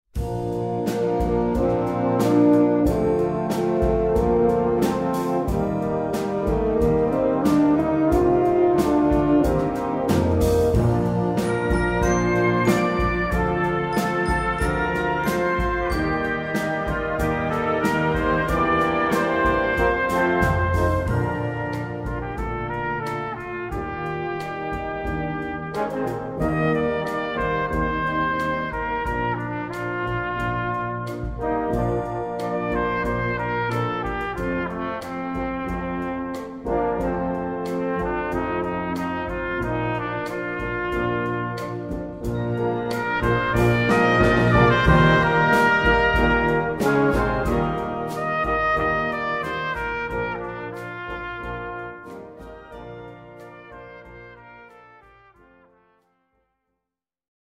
Gattung: Solo für Trompete
Besetzung: Blasorchester